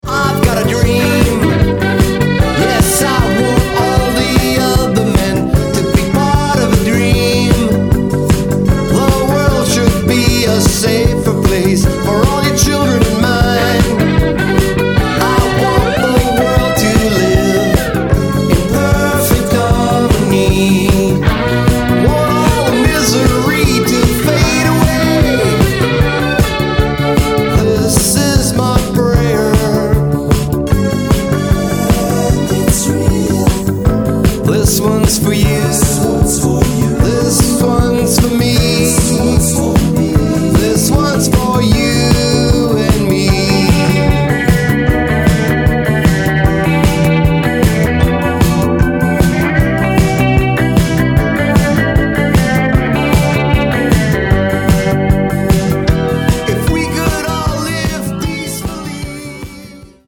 Four brothers and a cousin who play music.